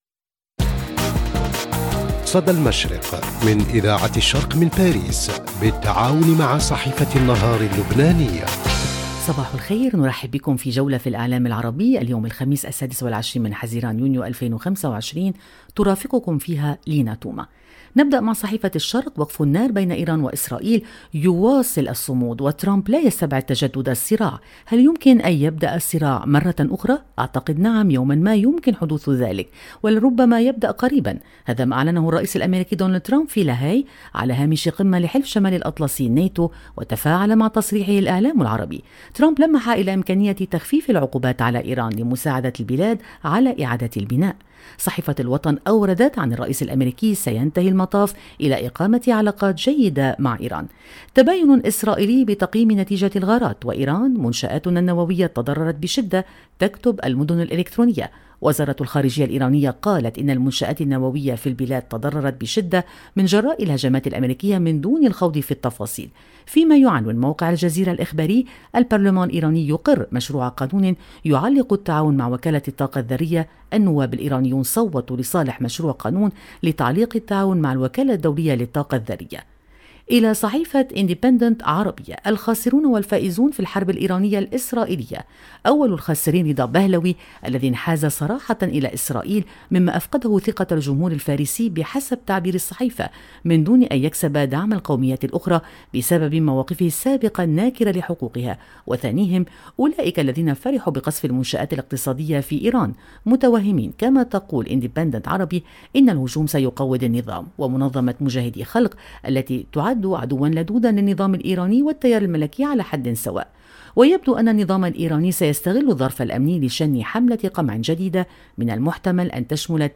صدى المشرق – نافذتك اليومية على إعلام الشرق، كل صباح على إذاعة الشرق بالتعاون مع جريدة النهار اللبنانية، نستعرض أبرز ما جاء في صحف ومواقع الشرق الأوسط والخليج من تحليلات ومواقف ترصد نبض المنطقة وتفكك المشهد الإعلامي اليومي.